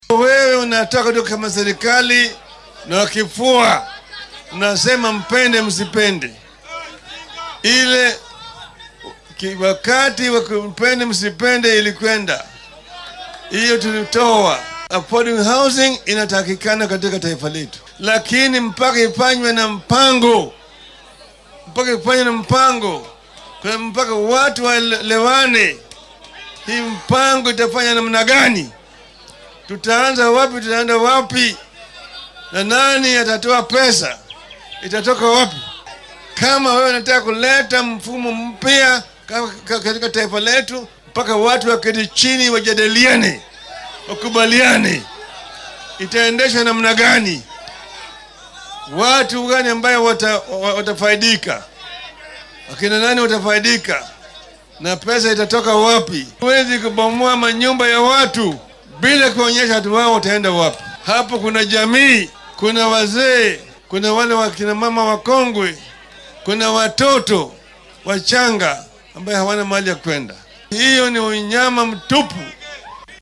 Mr. Odinga ayaa xilli uu ku sugnaa ismaamulka Mombasa ee gobolka Xeebta sheegay in tallaabooyinka uu madaxweynaha qaadaya ay yihiin kuwo baal marsan dastuurka.